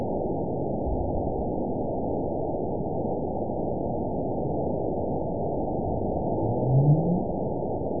event 920751 date 04/07/24 time 08:33:29 GMT (1 year, 1 month ago) score 9.55 location TSS-AB02 detected by nrw target species NRW annotations +NRW Spectrogram: Frequency (kHz) vs. Time (s) audio not available .wav